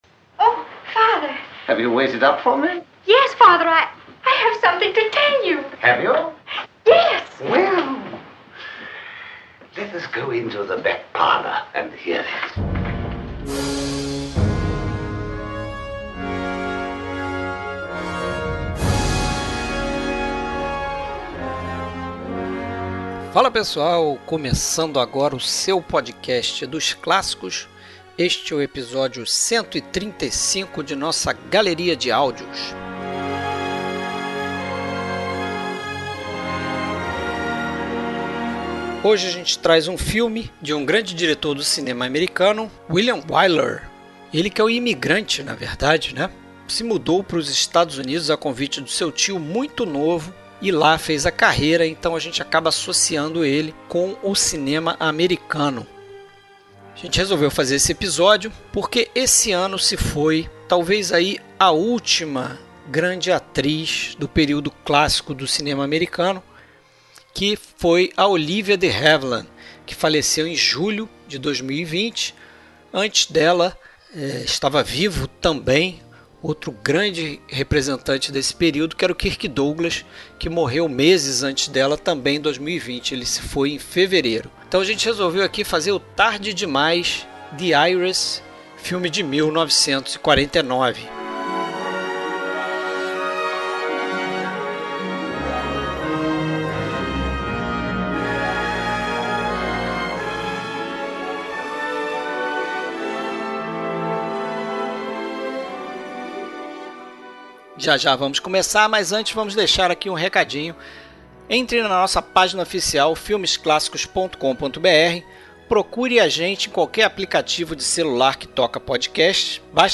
Trilha Sonora: Trilhas utilizadas no filme em questão.